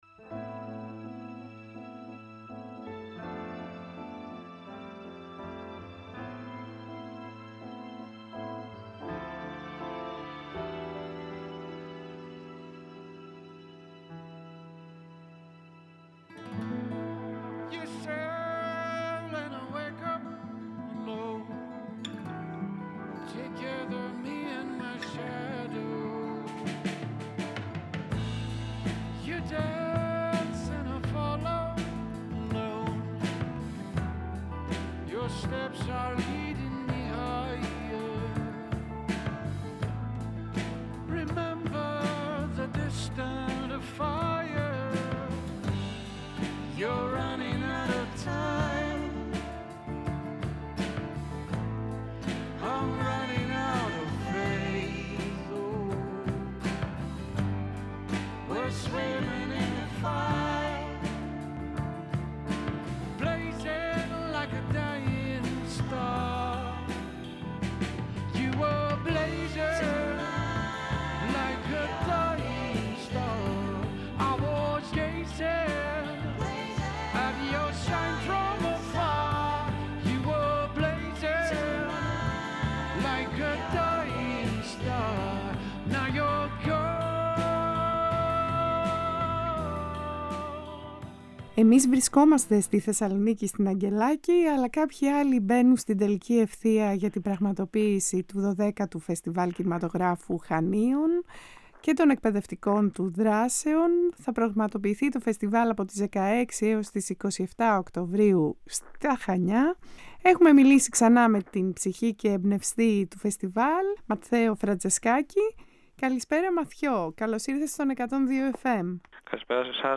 Μεταδίδεται ζωντανά κάθε Παρασκευή 20:00- 21:00 στον 102fm.